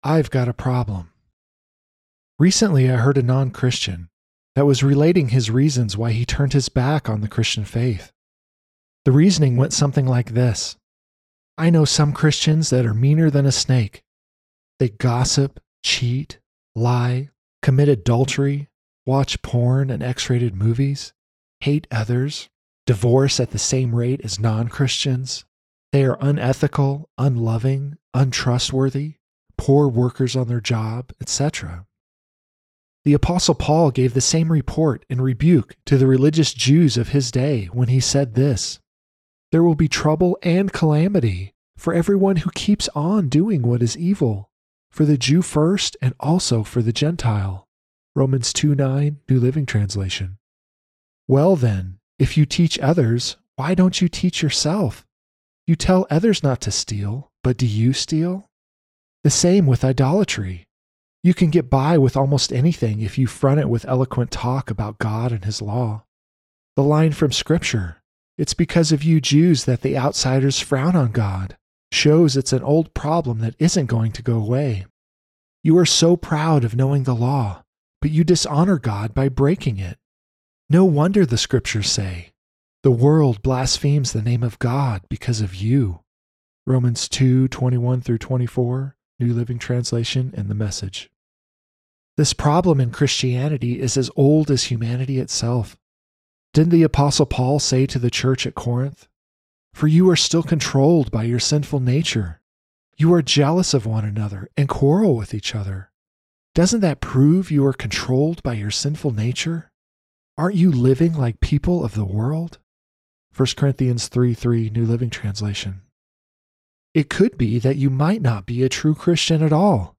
Audio Lesson